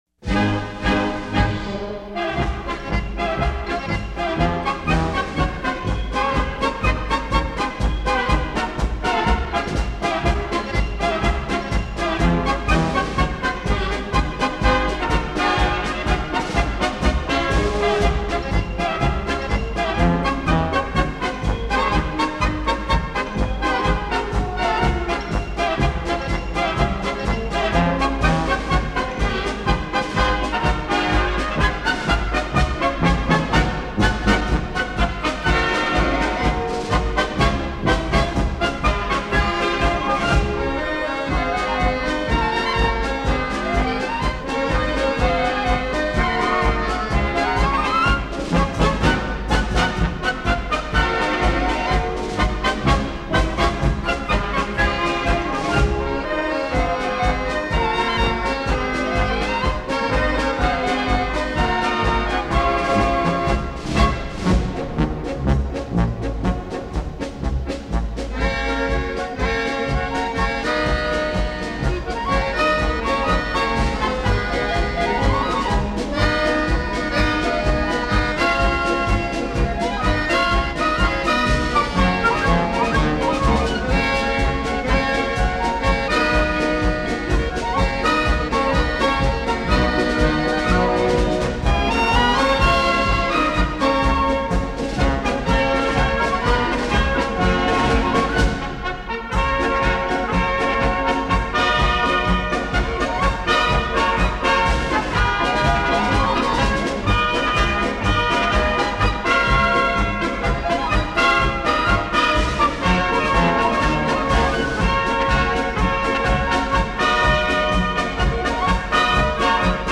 波尔卡（Polka），是捷克的一种民间舞蹈，这种舞蹈的舞曲也称作波尔卡。
前12首曲为铜管乐
本次先欣赏铜管乐演奏